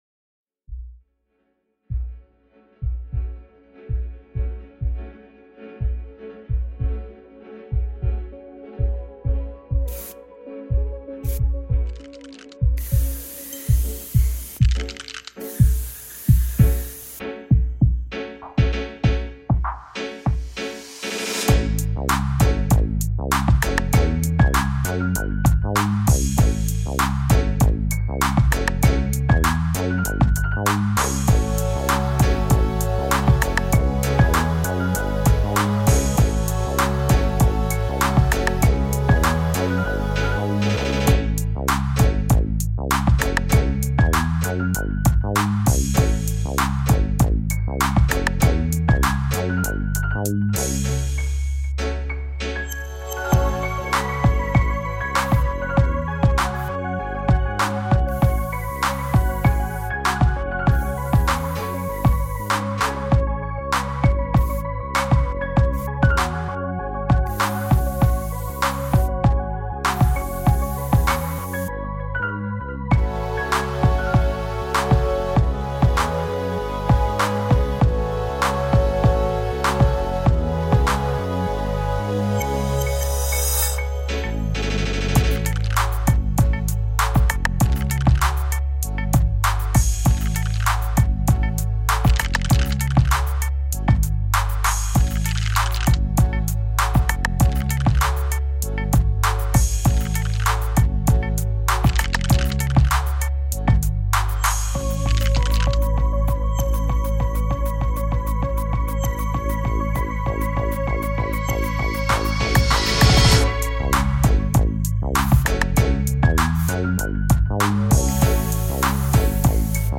bandeson